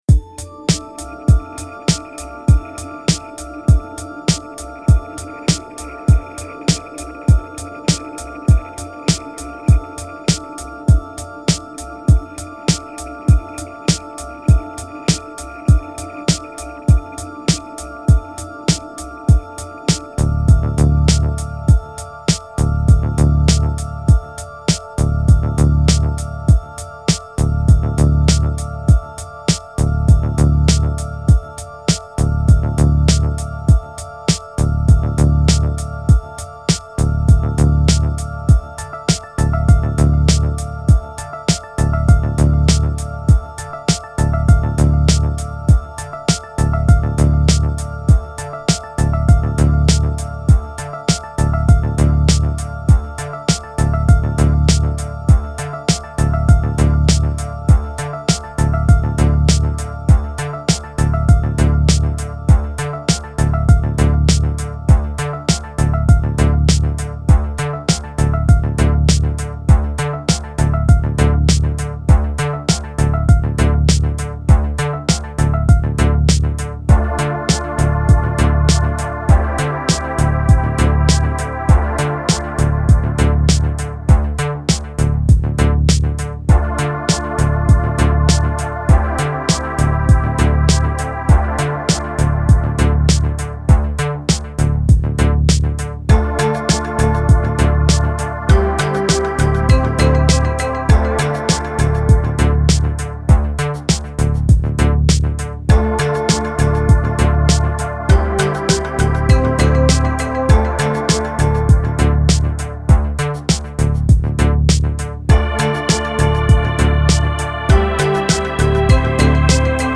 Genre: Chillout, Lounge, Downtempo.